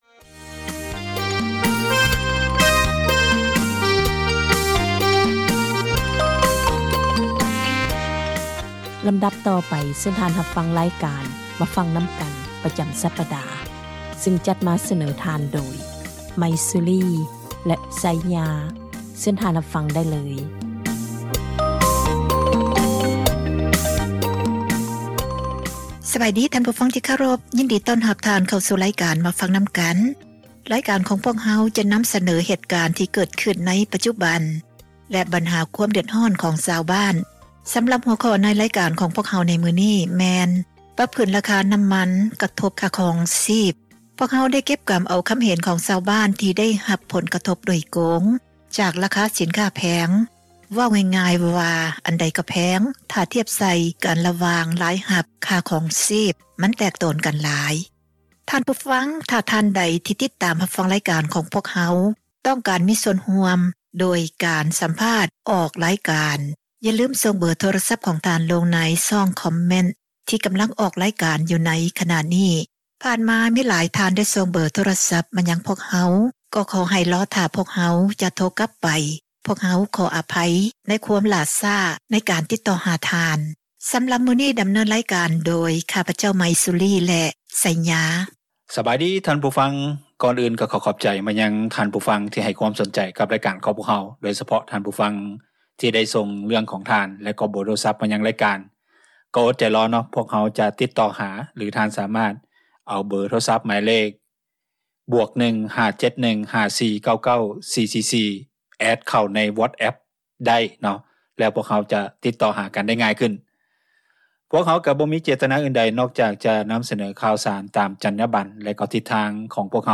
ພວກເຮົາ ໄດ້ຮິບໂຮມ ເອົາຄຳເຫັນ ຂອງຊາວບ້ານ ທີ່ໄດ້ຮັບ ຜົລກະທົບ ໂດຍກົງ ຈາກລາຄາສິນຄ້າແພງ ເວົ້າງ່າຍໆວ່າ ອັນໃດກະແພງ ຖ້າທຽບໃສ່ກັນ ລະຫວ່າງ ລາຍຮັບ ກັບຄ່າຄອງຊີບ ມັນແຕກໂຕນກັນຫລາຍ.